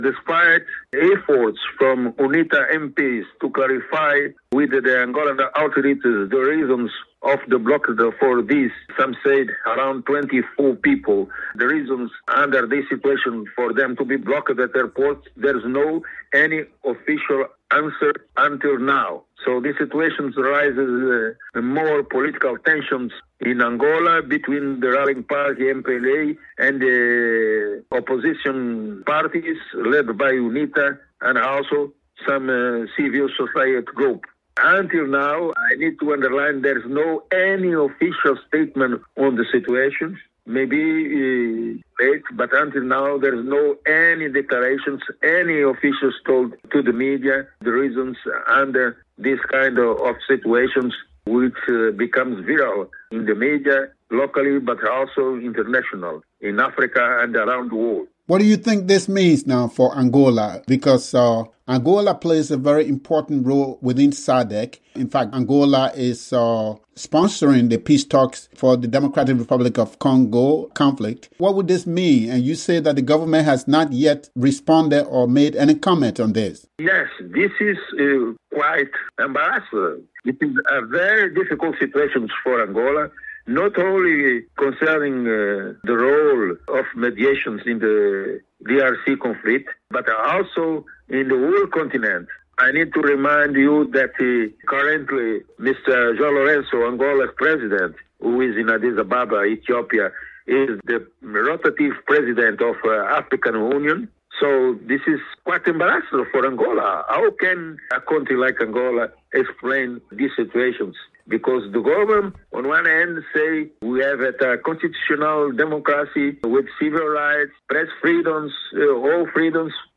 Veteran Angolan independent journalist